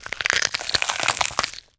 Flesh Rip 5 Sound
horror